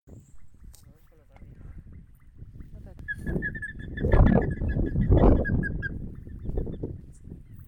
Plumbeous Ibis (Theristicus caerulescens)
Location or protected area: Esteros del Iberá
Condition: Wild
Certainty: Observed, Recorded vocal
Bandurria-Mora.mp3